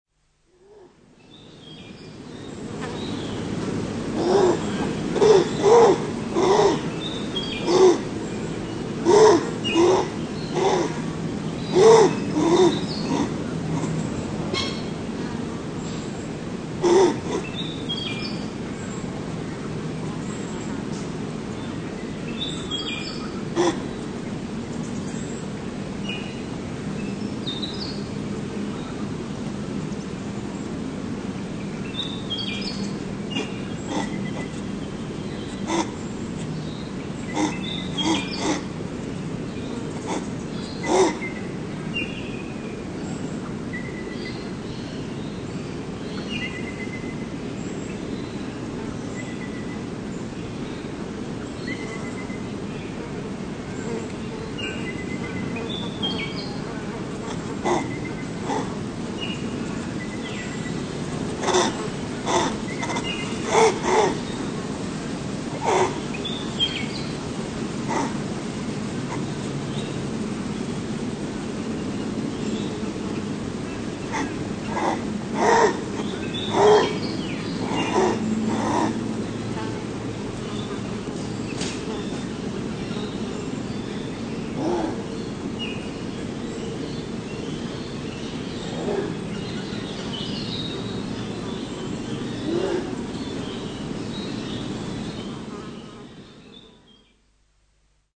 Звуки кенгуру
Голос взрослой самки рыжего кенгуру